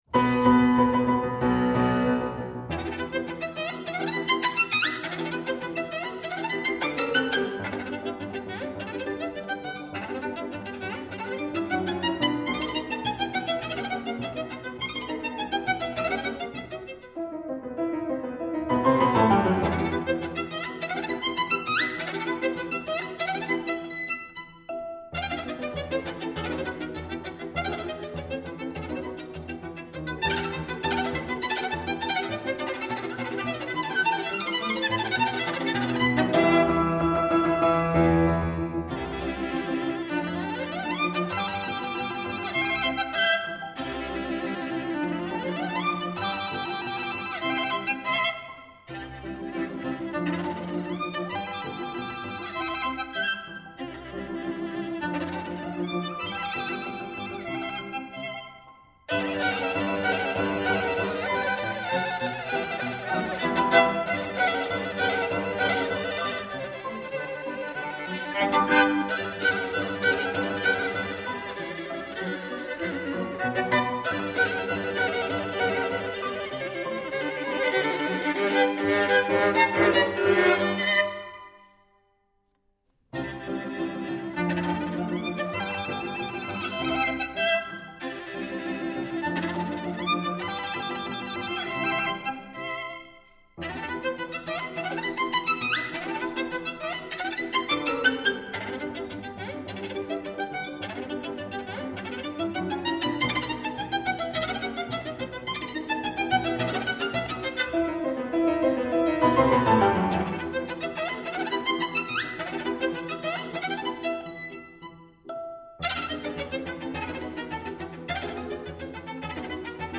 violin
piano
琴声优美！旋律好听！
它迷路了吗?不过最后情绪又恢复了原先快乐的状态,打消了大家对它的担忧。
在精彩的钢琴配合下,本曲显得十分有趣，动感十足。